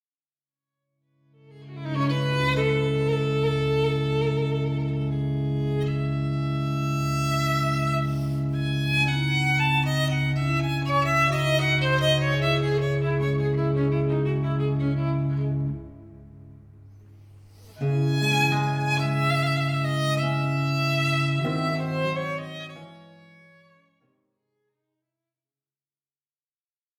ces sonates dites "du Rosaire" pour violon et basse continue